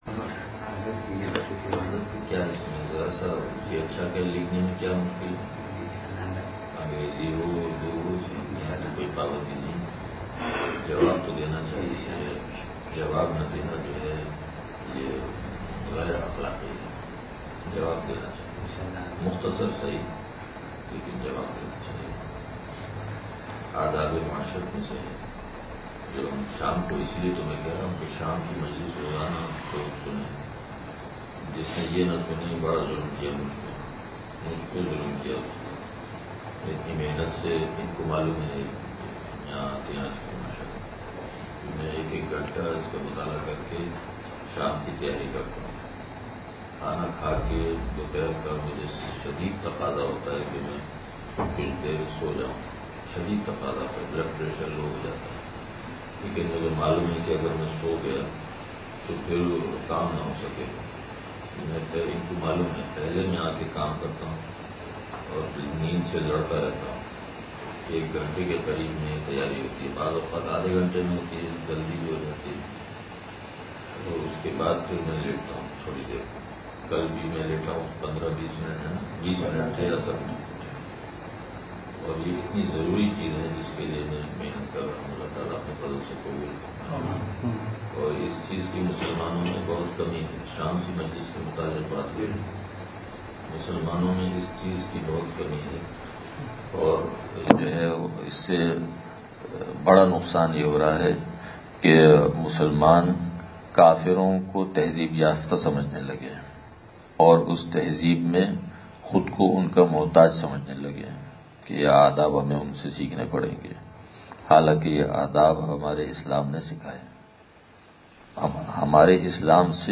مجلس اتوار – نشر الطیب فی ذکر النبی الحبیب صلی اللہ علیہ وسلم